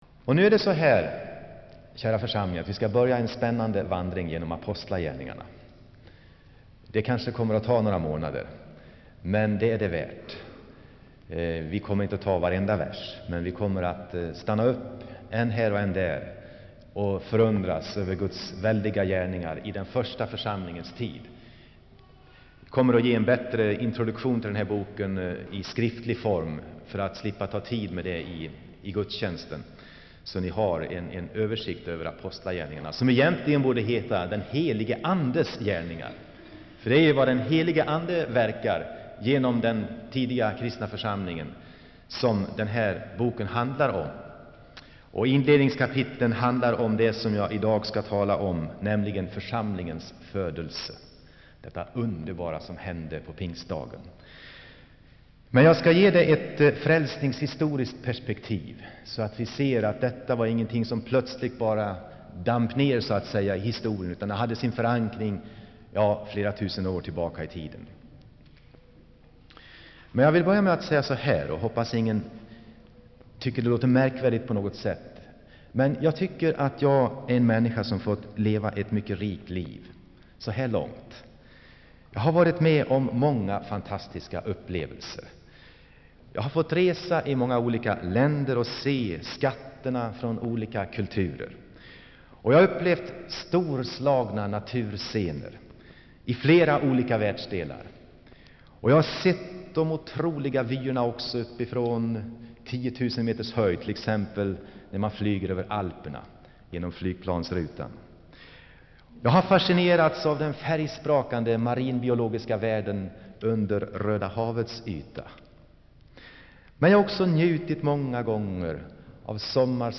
Inspelad i Saronkyrkan, Göteborg.